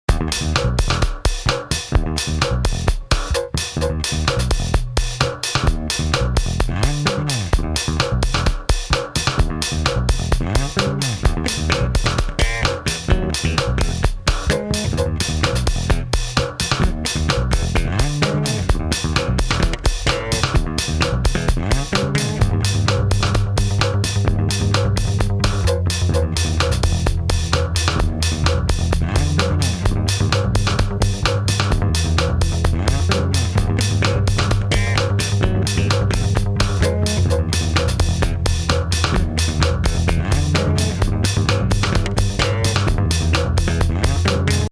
orginal 3 bass grooves